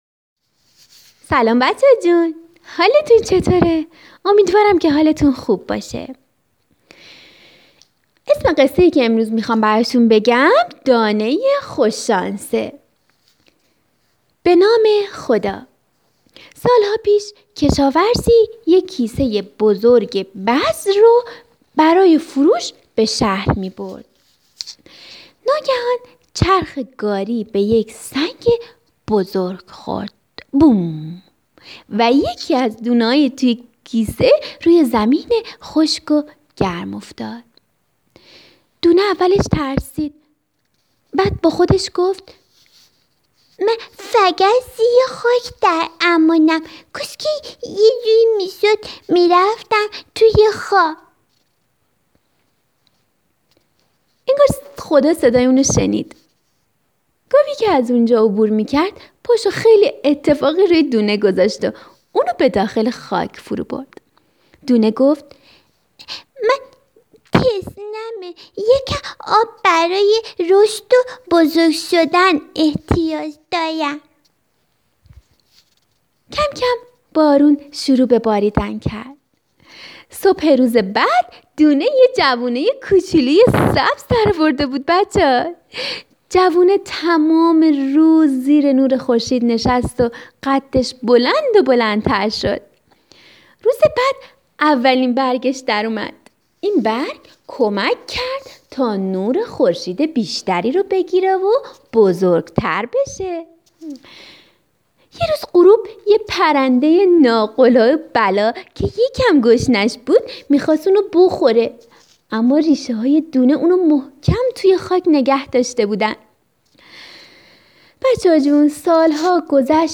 قصه‌ی دانه‌ی خوش شانس - رادیو قصه صوتی کودکانه